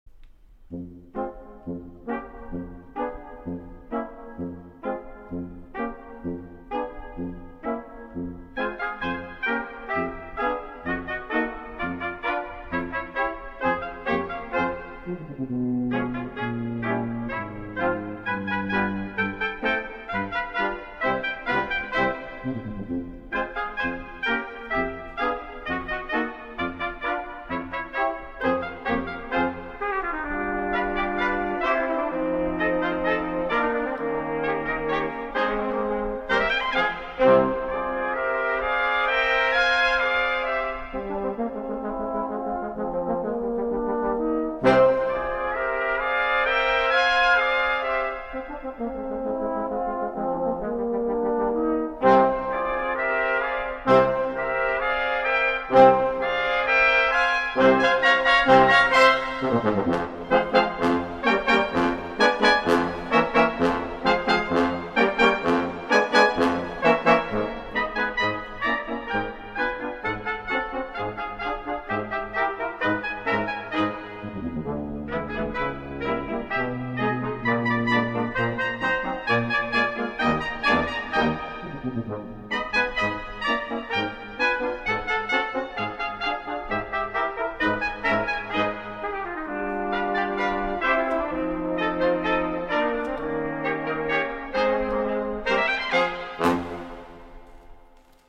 Voicing: 11 Brass